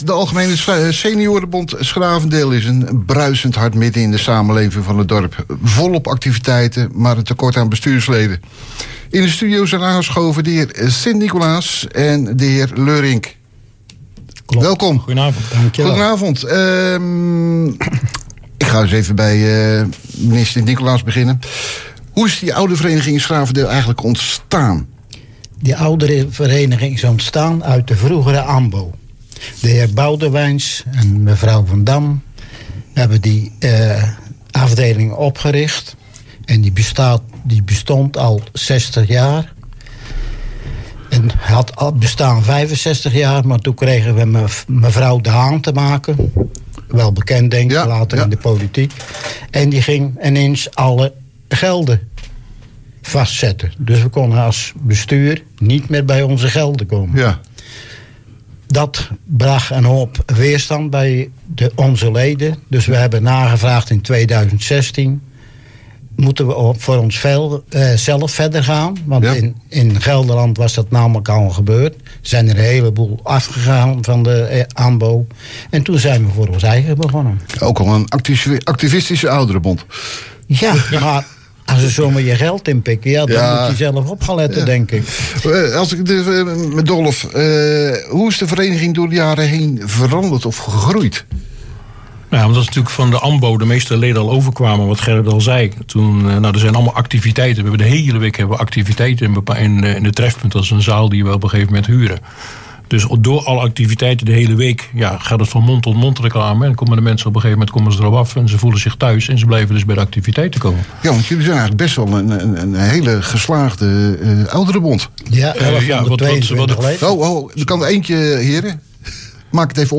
De Algemene Seniorenbond ‘s-Gravendeel is een bruisend hart midden in de samenleving van ‘s-Gravendeel. Volop activiteiten, maar een tekort aan bestuursleden. In het programma Hoeksche Waard Actueel spraken wij